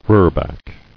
[roor·back]